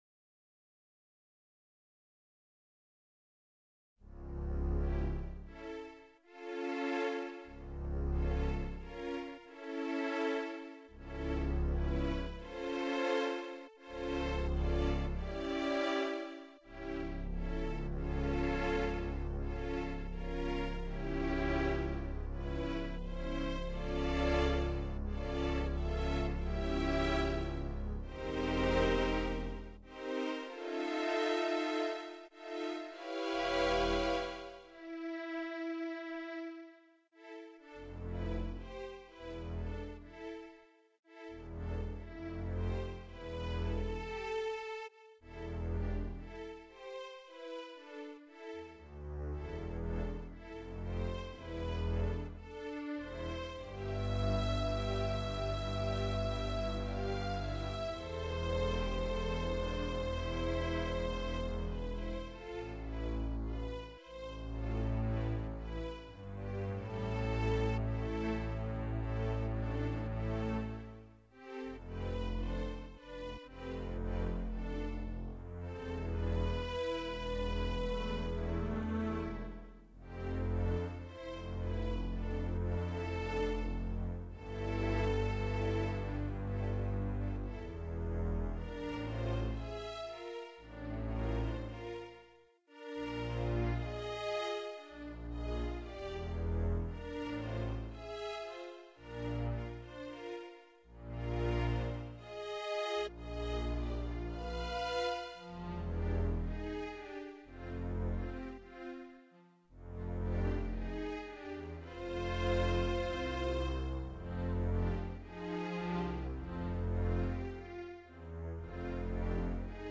Bgm Music Tune to video game in one of level areas.